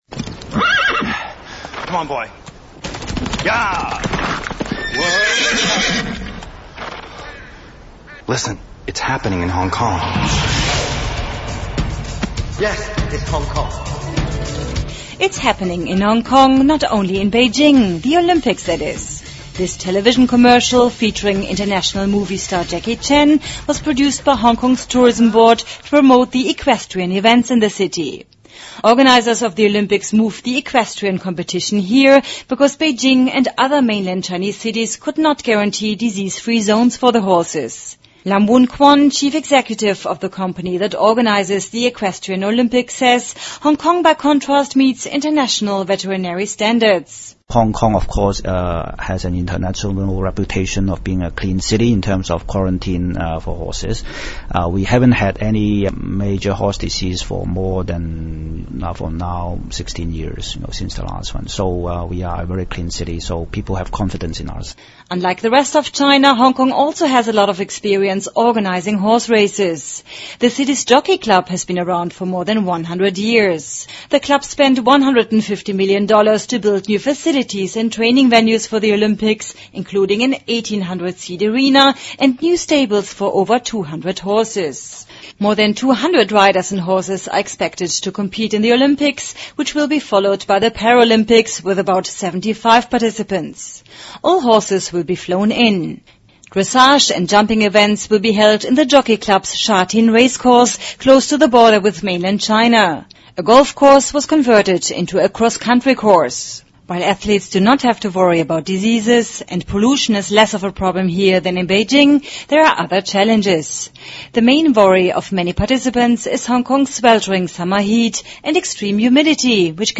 香港举办奥运马术赛一切筹备就绪|英语新闻听力
News